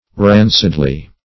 rancidly - definition of rancidly - synonyms, pronunciation, spelling from Free Dictionary Search Result for " rancidly" : The Collaborative International Dictionary of English v.0.48: Rancidly \Ran"cid*ly\ (r[a^]n"s[i^]d*l[y^]), adv.
rancidly.mp3